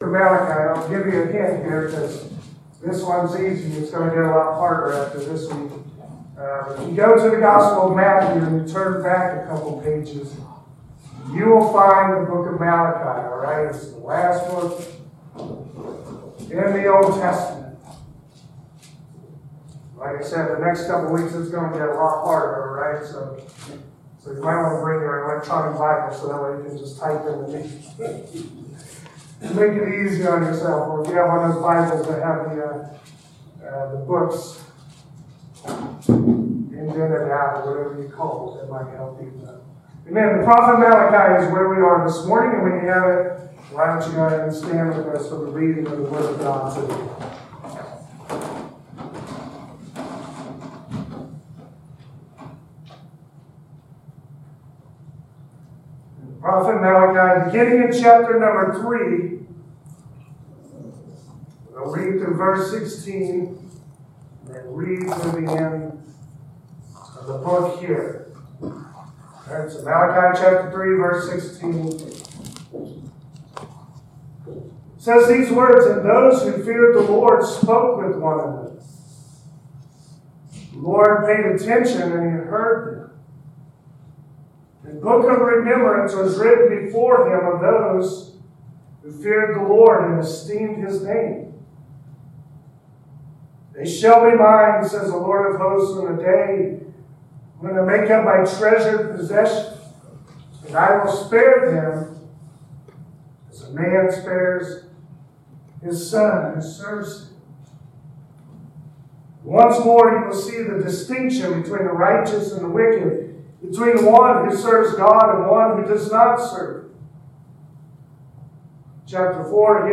Malachi 3:16-4:6 Service Type: Sunday Morning Malachi 3:16-4:6.